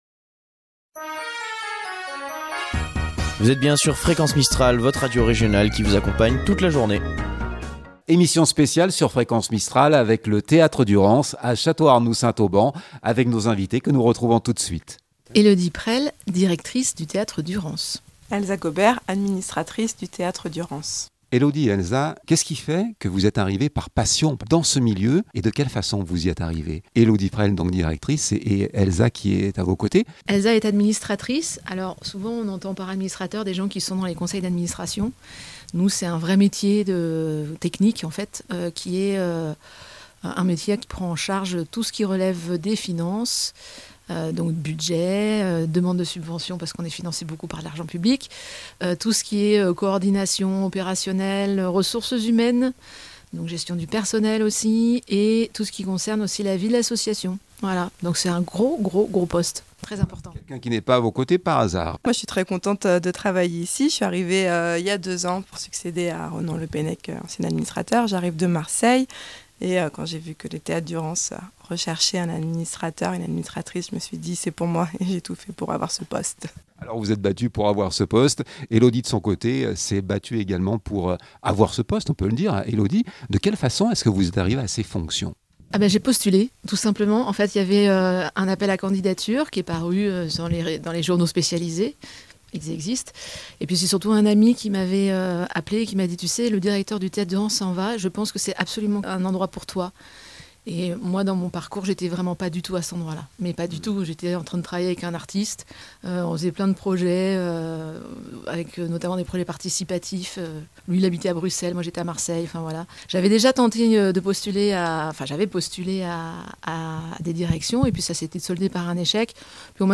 2025-06-02 - Emission Théatre Durance.mp3 (33.76 Mo) Émission spéciale sur Fréquence Mistral: Dans les coulisses du Théâtre Durance à Château-Arnoux Saint Auban.